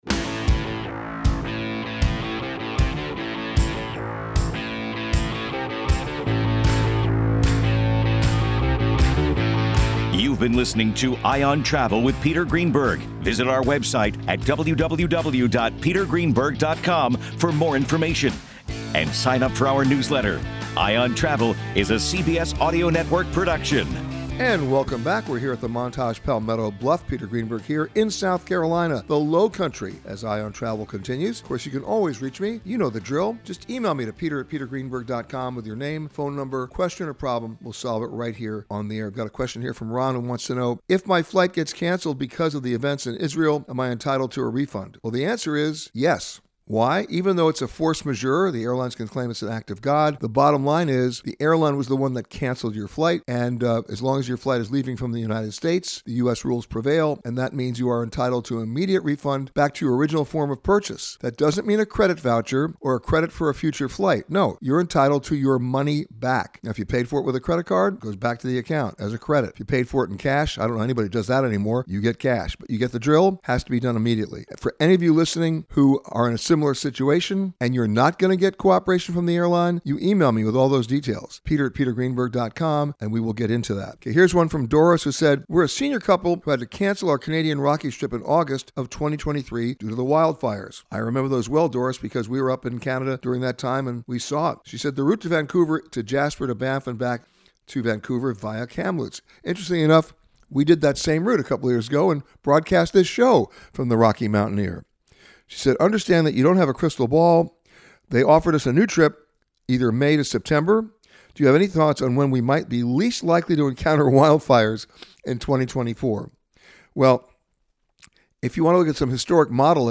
This week, Peter answered your questions from The Montage Palmetto Bluff in Bluffton, South Carolina. Peter answers your questions on avoiding wildfires, when to book airfares to the Bahamas, going through security, and more.